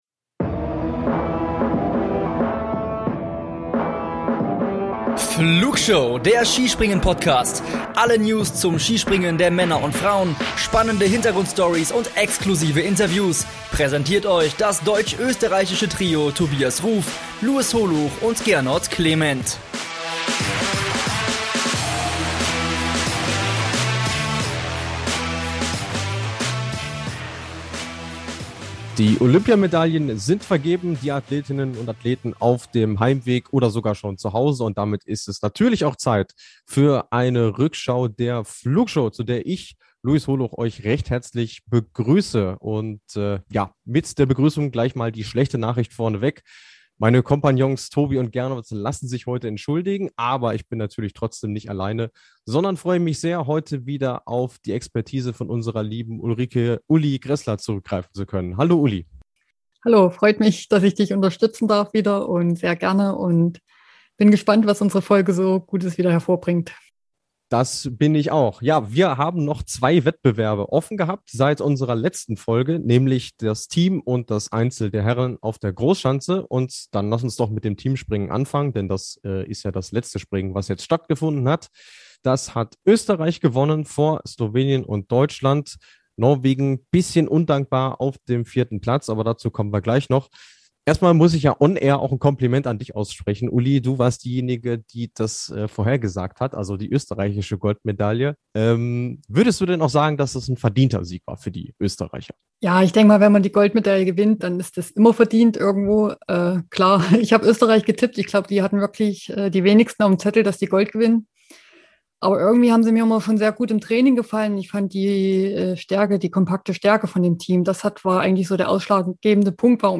Die Skisprung-Wettkämpfe bei den Olympischen Spielen 2022 in Peking sind Geschichte. Und als wären die Spiele als solche nicht schon aufreibend genug gewesen, crashen auch noch die Stürme "Xandra" und "Ylenia" die Aufnahme.